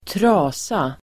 Uttal: [²tr'a:sa]